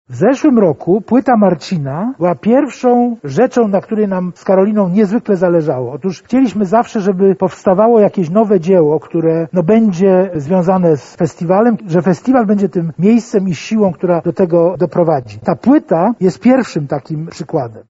Podczas konferencji w Teatrze Starym poznaliśmy szczegóły zbliżających się wydarzeń.